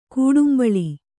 ♪ kūḍumbaḷi